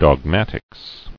[dog·mat·ics]